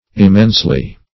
Immensely \Im*mense"ly\, adv.